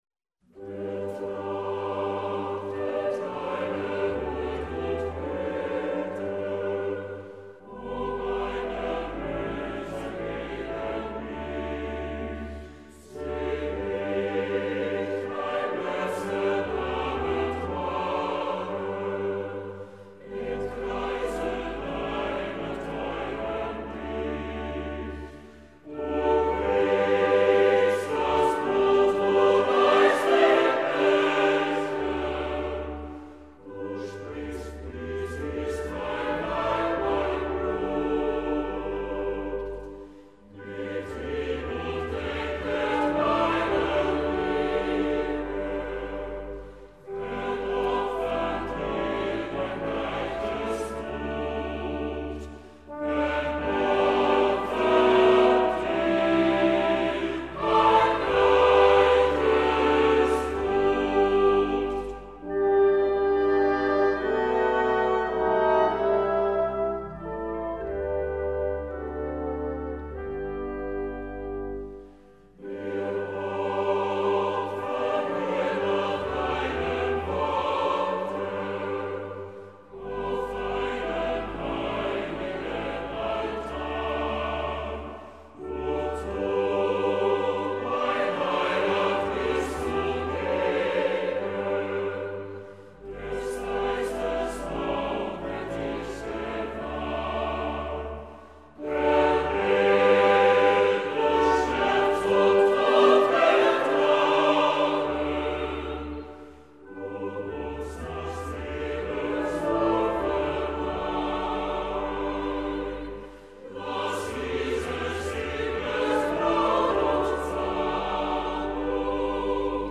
2 Symfonieorkest van de Beierse omroep o.l.v. Wolfgang Sawallisch